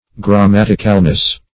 Gram*mat"ic*al*ness, n.
grammaticalness.mp3